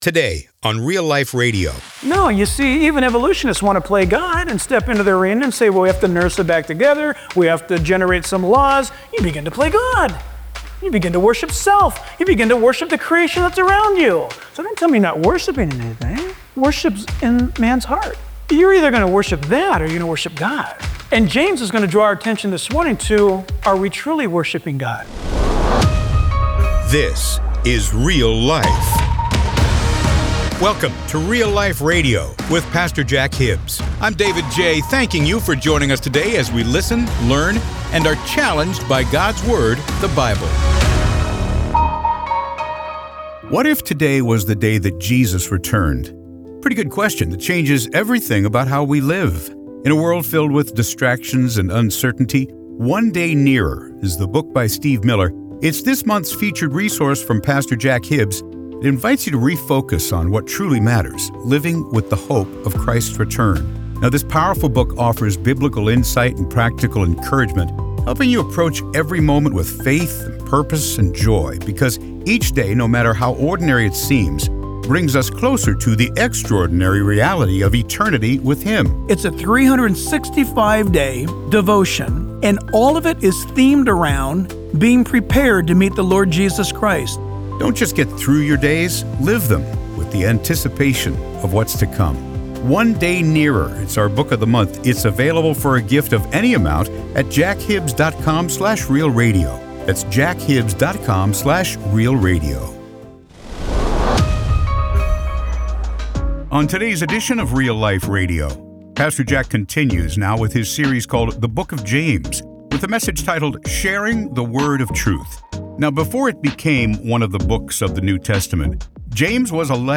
His bold preaching will encourage and challenge you to walk with Jesus.